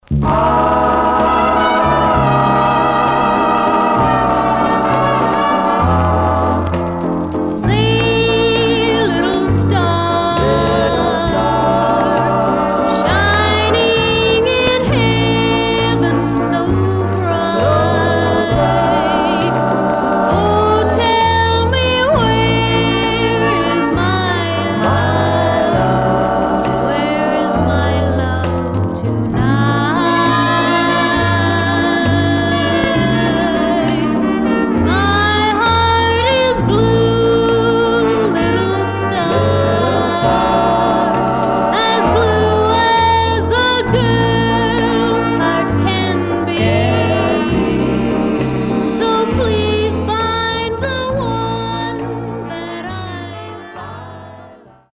Surface noise/wear Stereo/mono Mono
Teen